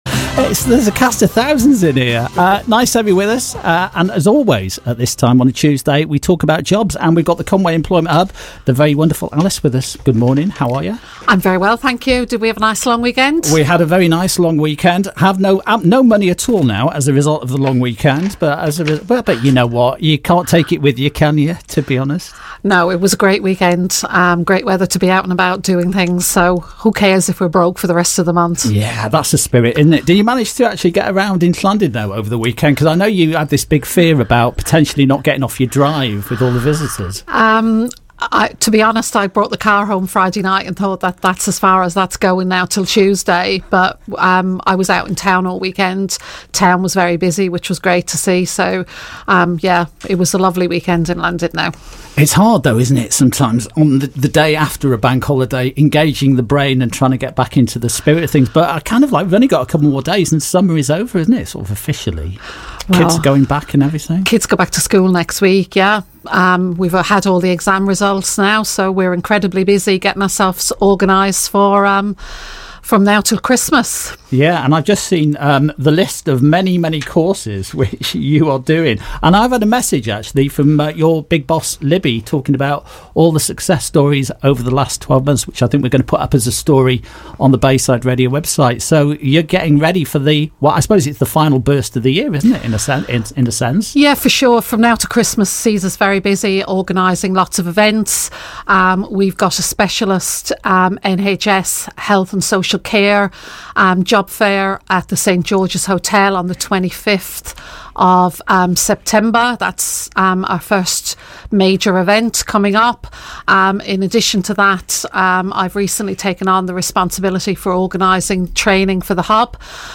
Bayside Radio Interview: Ethical Workforce Solutions & Conwy Employment Hub Announce New Social Care Course